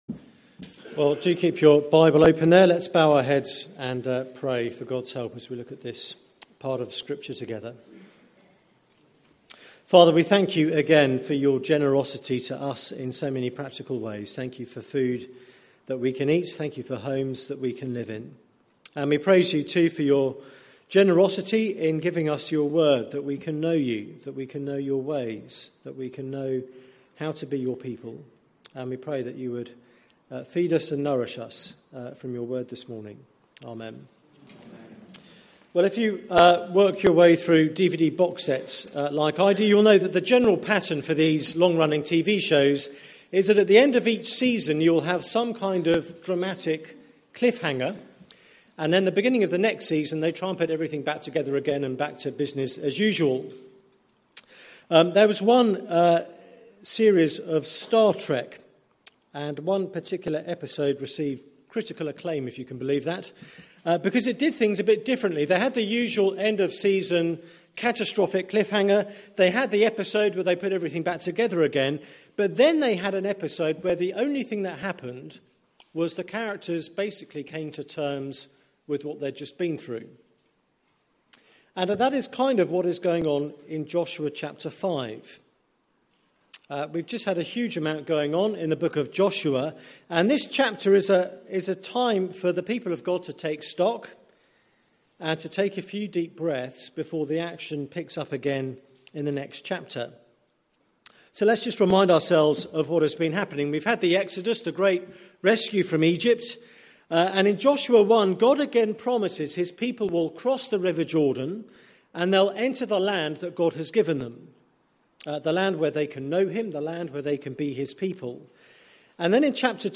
Media for 9:15am Service on Sun 25th Sep 2016 09:15
Series: Great Is Thy Faithfulness Theme: The People of God Sermon